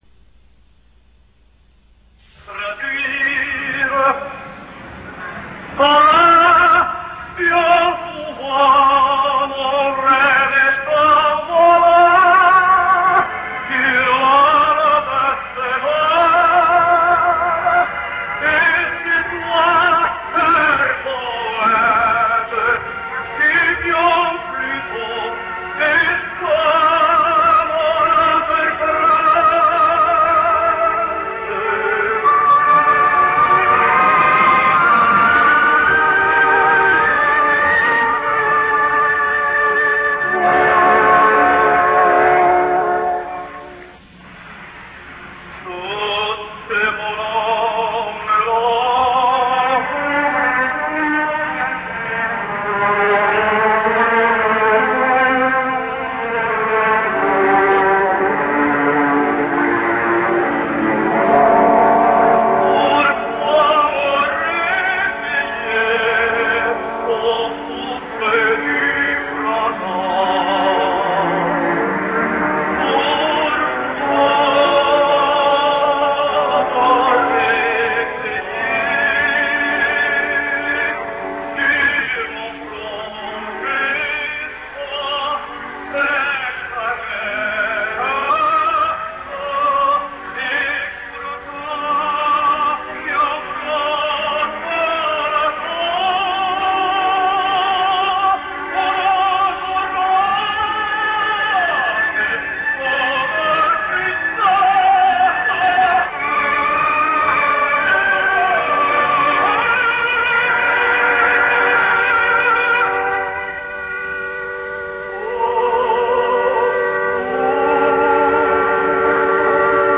A resplendent voice, an interesting, slightly veiled timbre, yet with a brilliant, easy top; a thrilling interpreter of utmost musicality; virile yet able of the most delicate accents; excellent diction in various languages: this man had it all.
unpublished live recordings